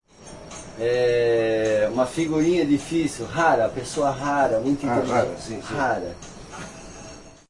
Tag: ecm907 现场录音 mzr50 讲话 谈话 语音